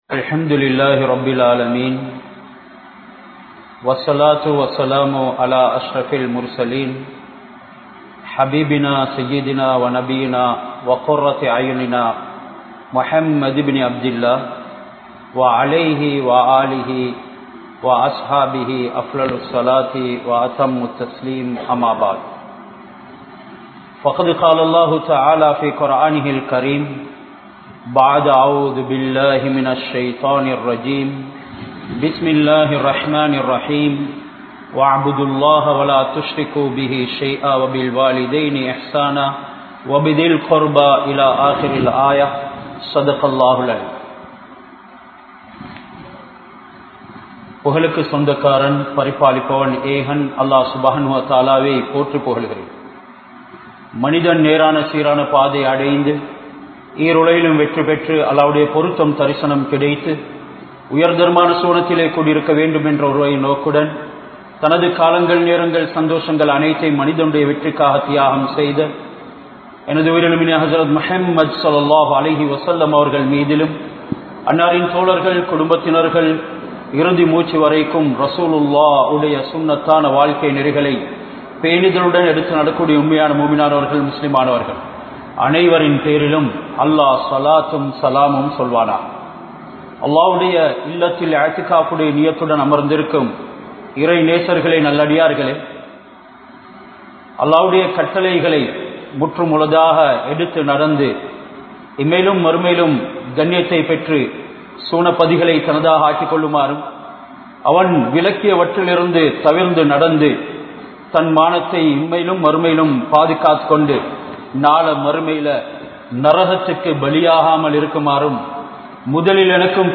Al Quran Sunnah vai Pin Pattruvathan Avasiyam (அல்குர்ஆன் ஸூன்னாவை பின்பற்றுவதன் அவசியம்) | Audio Bayans | All Ceylon Muslim Youth Community | Addalaichenai
Delgahagoda Jumua Masjidh